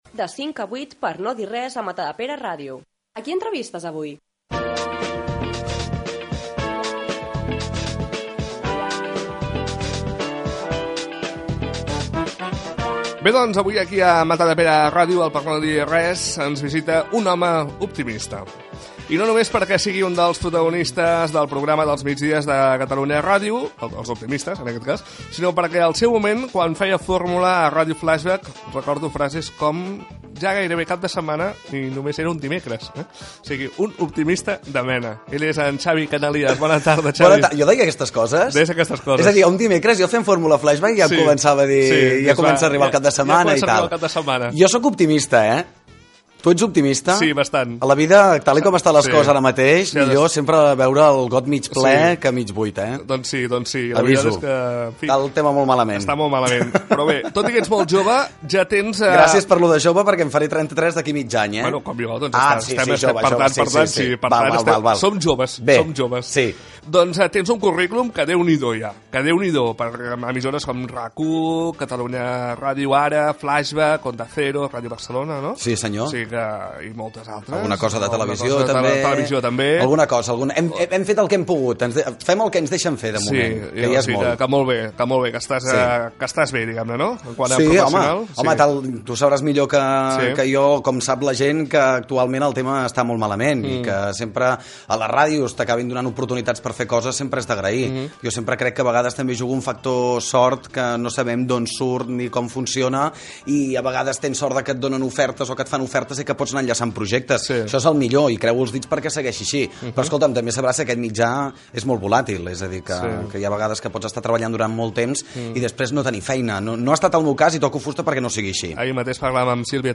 Indicatiu del programa
Gènere radiofònic Entreteniment